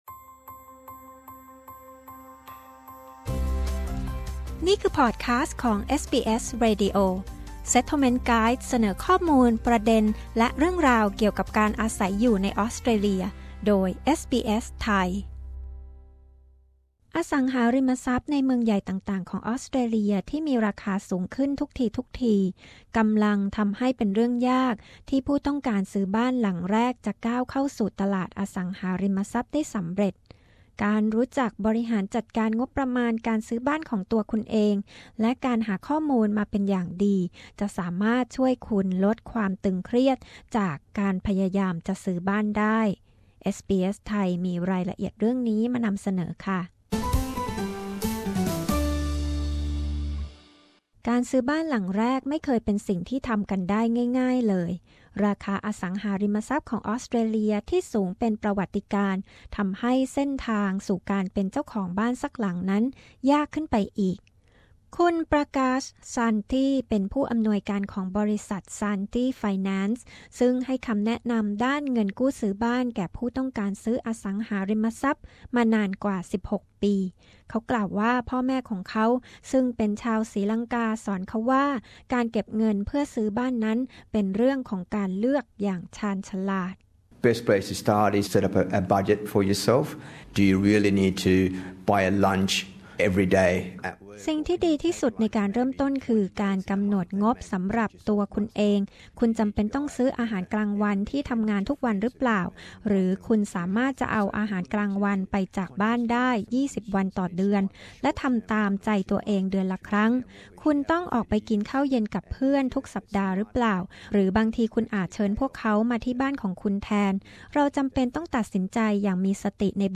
สารคดีพิเศษของเอสบีเอส แนะนำคุณตั้งแต่ขั้นแรกถึงขั้นสุดท้ายของการจะซื้อบ้านสักหลังในออสเตรเลีย ไม่ว่าจะเป็นการวางแผนเก็บเงินดาวน์บ้าน การหาข้อมูลเกี่ยวกับบ้าน และขั้นตอนการซื้อขายบ้านที่คุณควรรู้